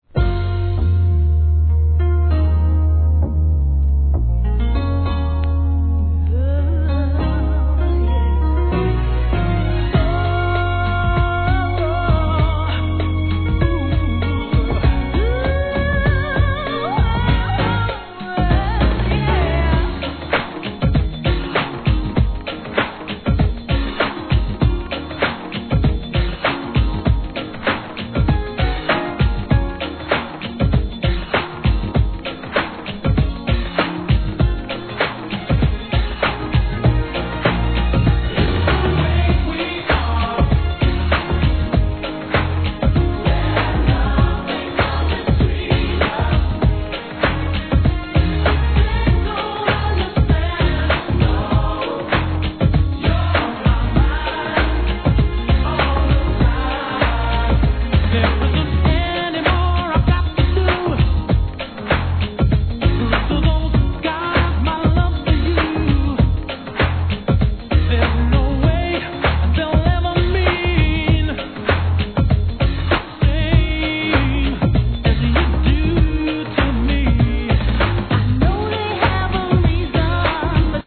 1. HIP HOP/R&B
UKらしい美メロ・グランドビート人気曲